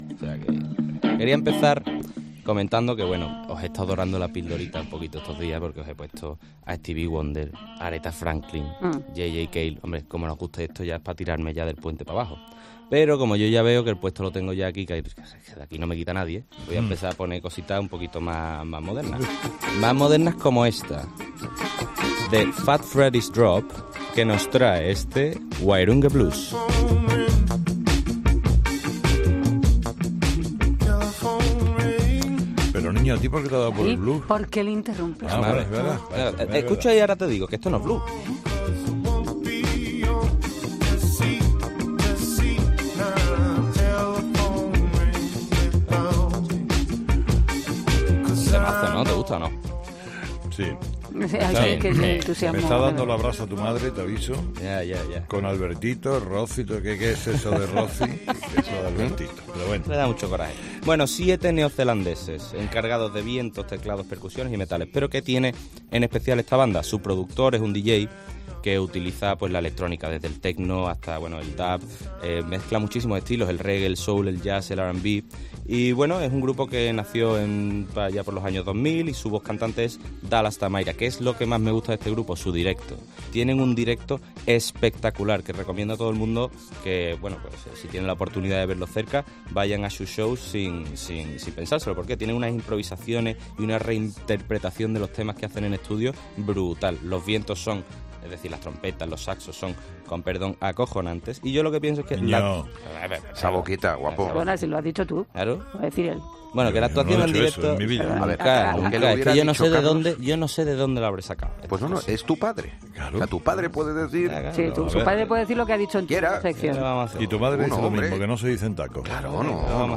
Un tema para arrancar el contenido y otro para poner el broche de oro.